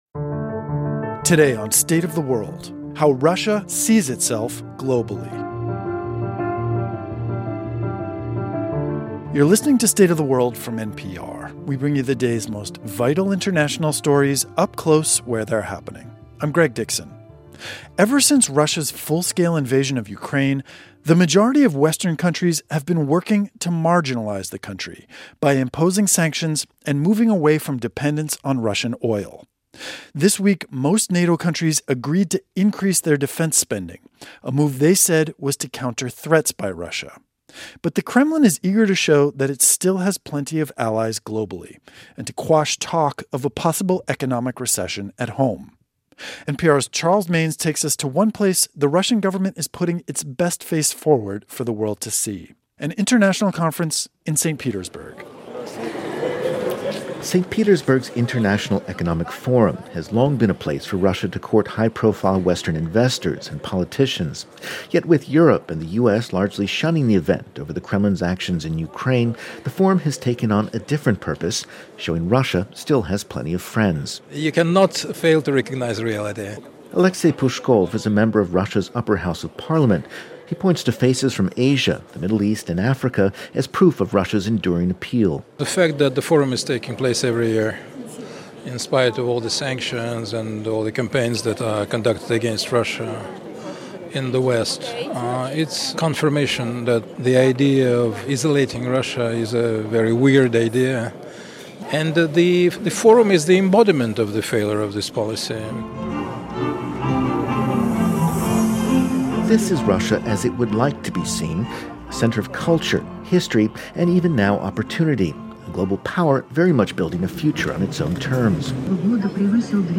Our correspondent takes us to an international conference in Saint Petersburg where the Russian government is putting it's best face forward.